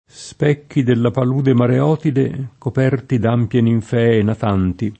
Mareotide [mare0tide] (poet. Mareoti [mare0ti]) top. f. stor.